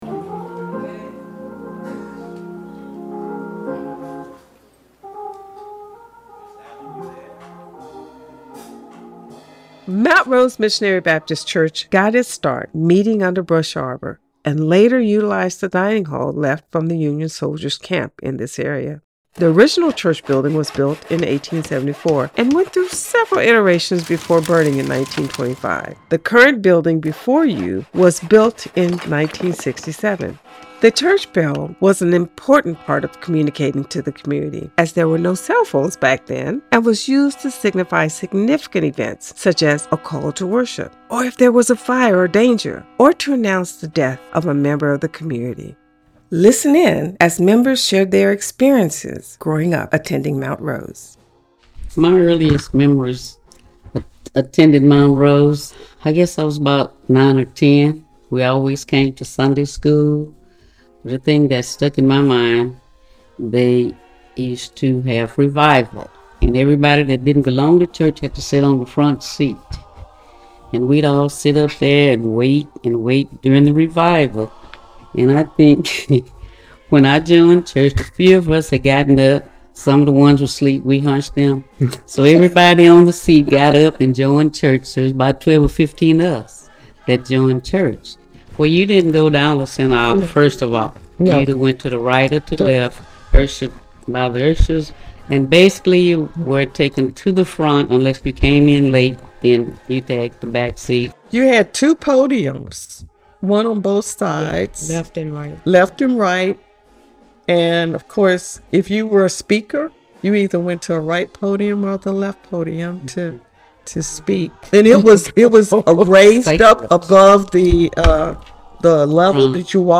You will go on a tour that includes sounds and stories of the area.